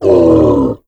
MONSTER_Pain_01_mono.wav